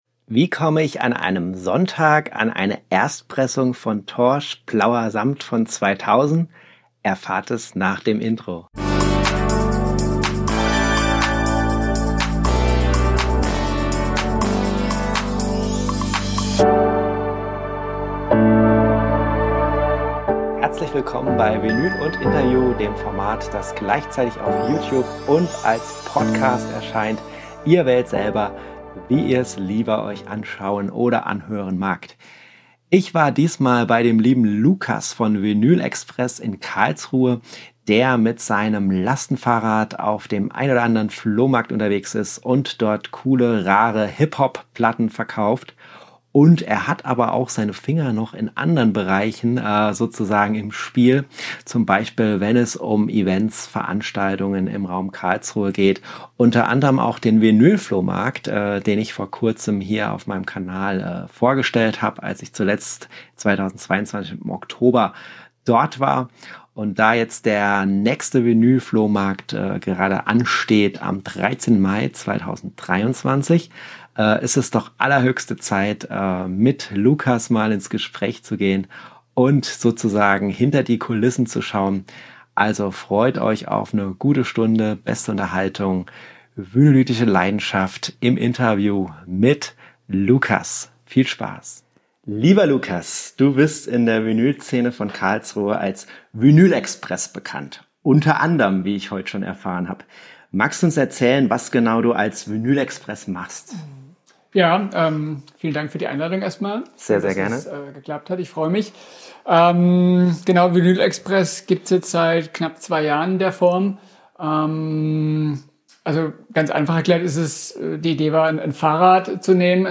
Höre zu, wenn zwei Vinylisten über ihr liebstes Hobby fachsimpeln und diversen Emotionen verfallen...